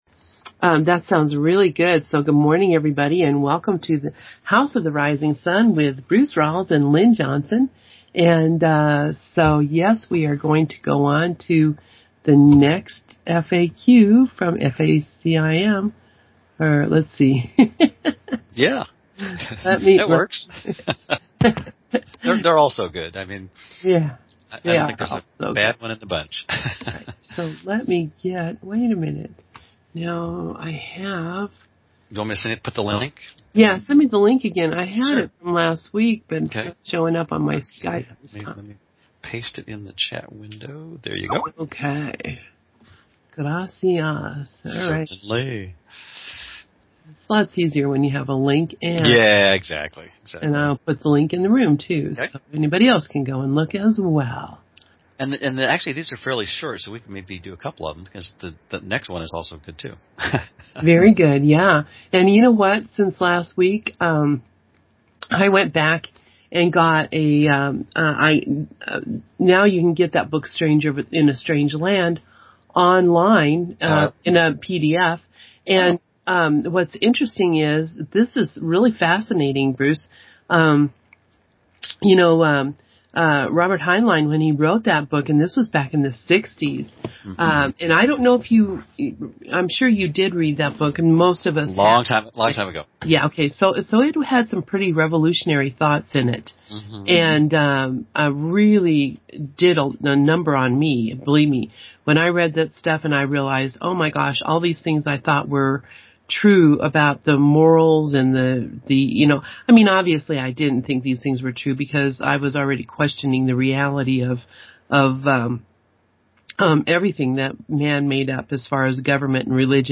FACIM FAQ #1163: a conversation